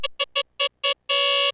Maked test chunk with a lot of magic string (silence) frames and sequences of 1, 2 ,3, 4, 5 and many instances of one frame from 837 chunk.
Update: captured again with 48kHz sample rate for better match with 8kHz discretization
Also noticed each tone burst is about 14 milliseconds longer than the multiple of 32 bytes per 16 msec; eg one repeat is 30 msec, 2 is 46 msec, 4 is 79 msec.